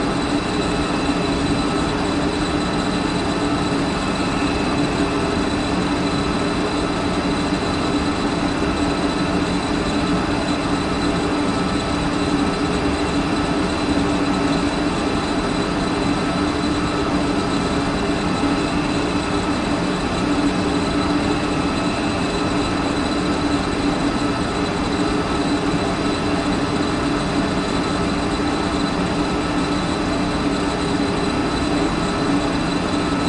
随机的 "泵工业在大房间和金属的响声
描述：泵房工业在大房间和金属拨浪鼓.flac
Tag: 金属 拨浪鼓 室内 工业